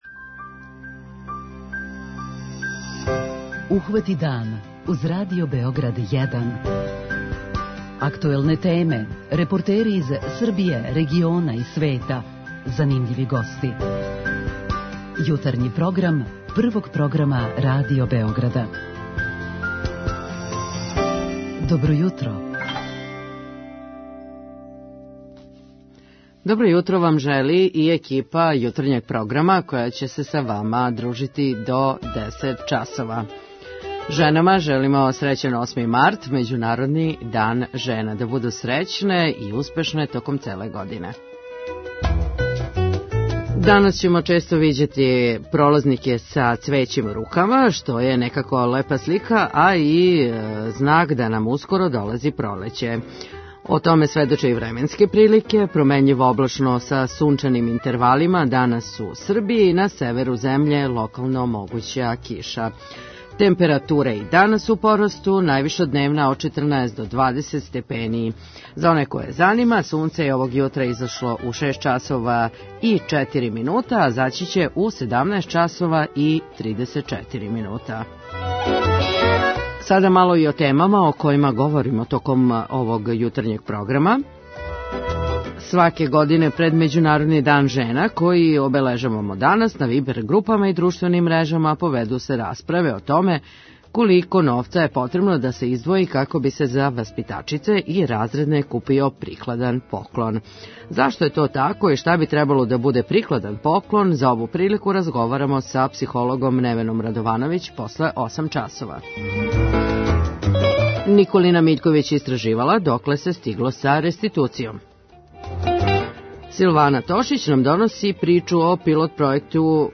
Група аутора Јутарњи програм Радио Београда 1!